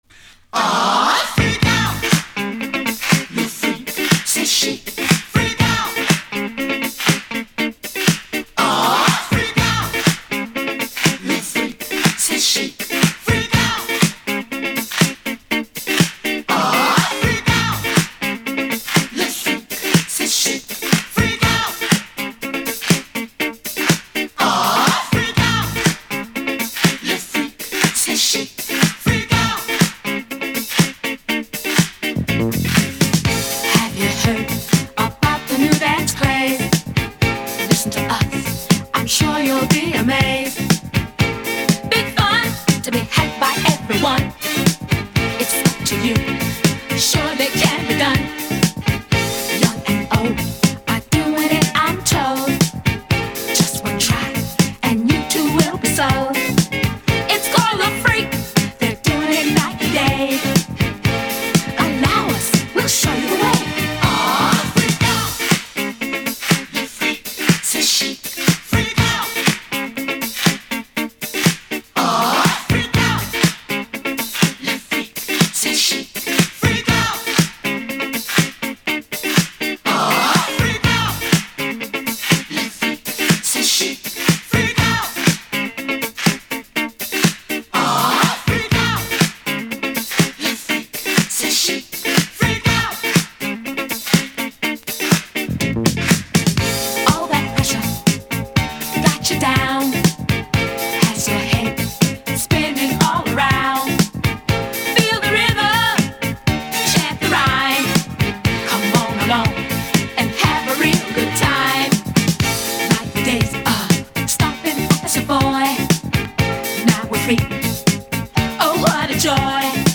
mixed as a continuous groove